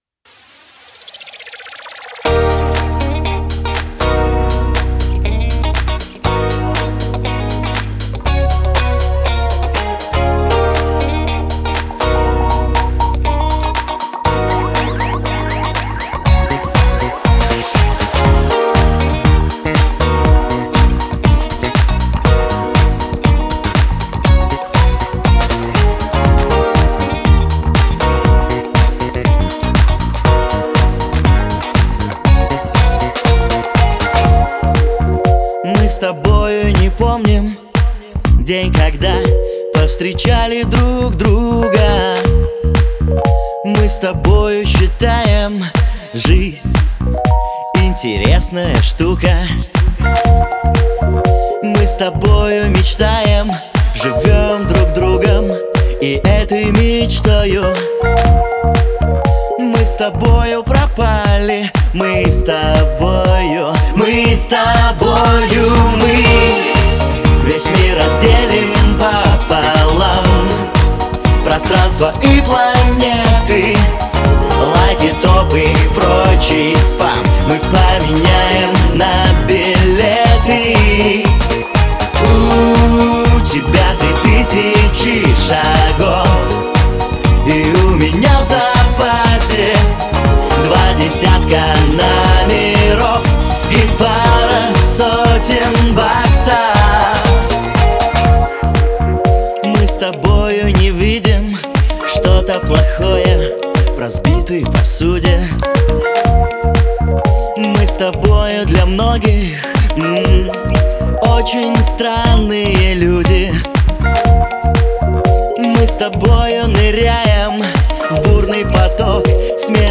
Еще одна битва лимитеров! ( Тест )